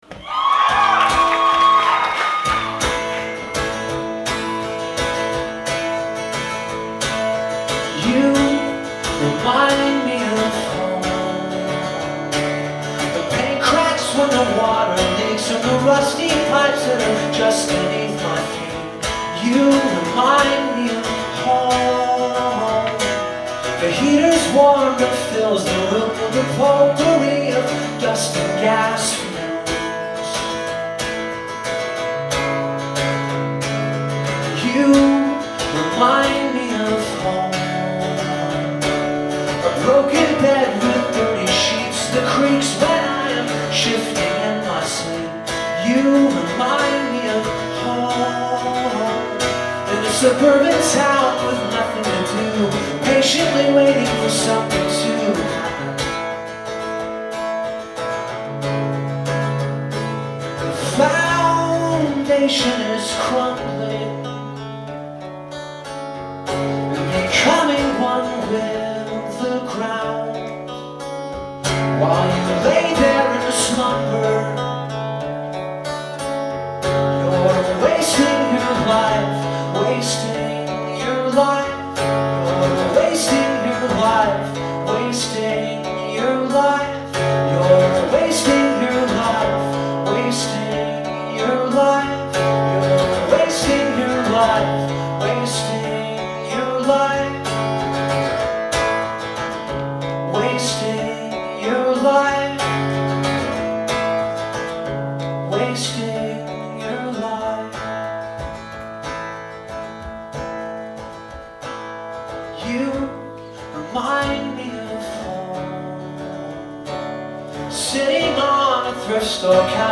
Live at the Somerville Theatre
in Somerville, Massachusetts